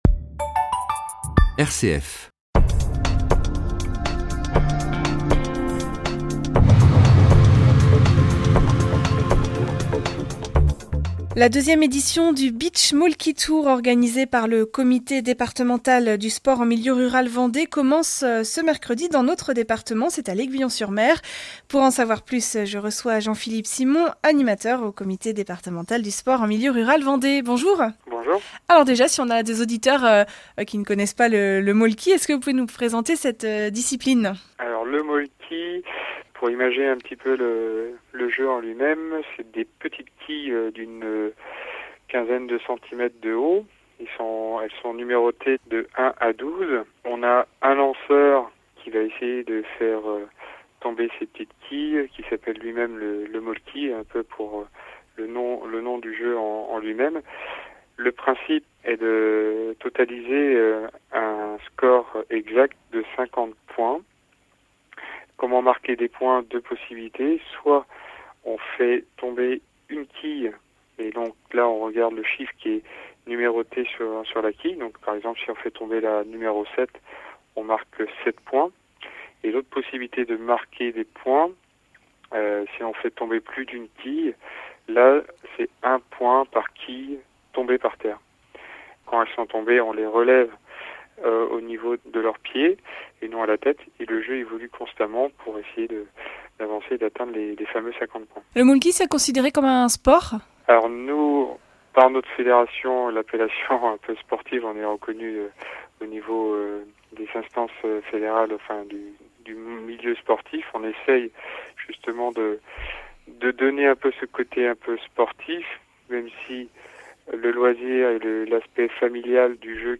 Pour ce dernier rendez-vous de la saison, Alternantes vous propose un lab’oratoire spécial agriculture urbaine qui prendra la forme d’un reportage.